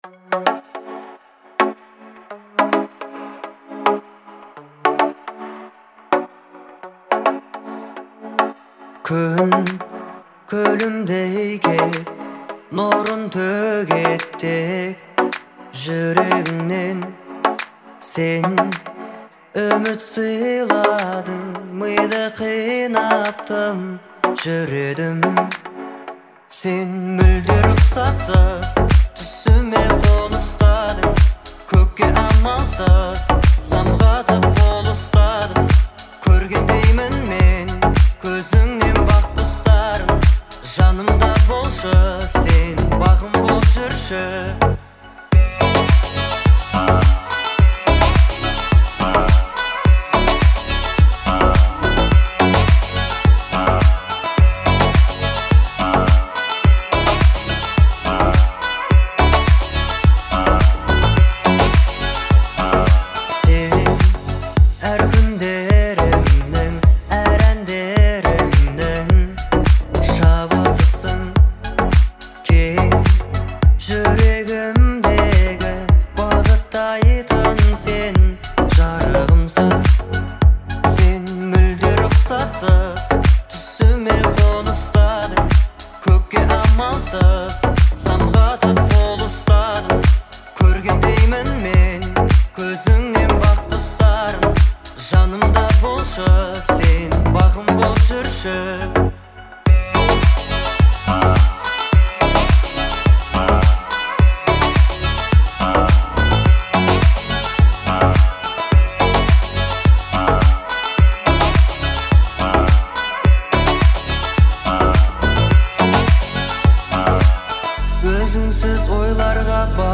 мелодичная и романтичная песня